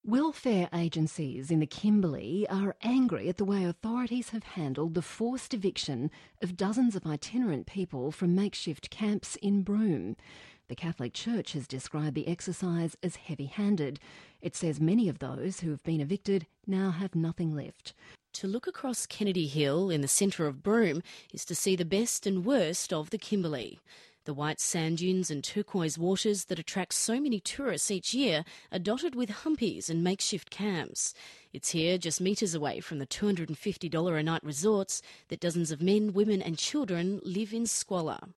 An excerpt from ABC 2010 report when people were moving into the Kennedy Hill area after being hunted off country by the WA government and mining companies.